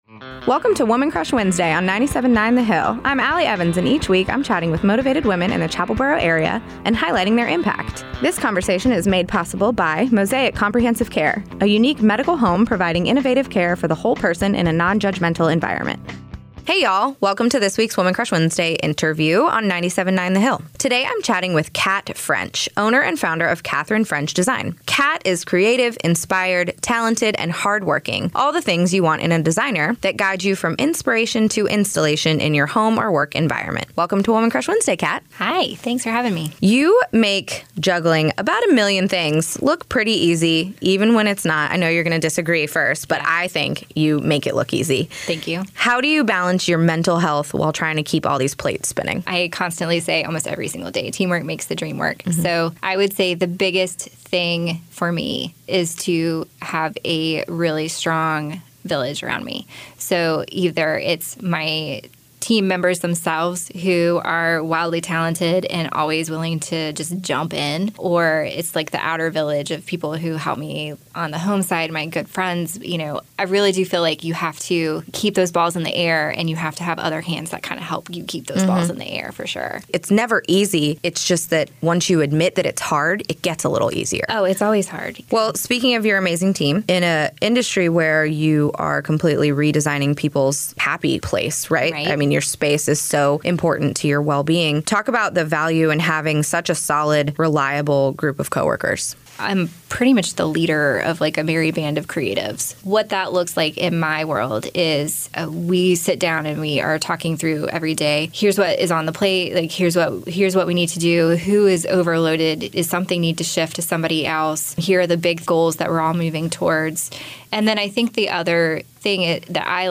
” a three-minute weekly recurring segment made possible by Mosaic Comprehensive Care that highlights motivated women and their impact both in our community and beyond.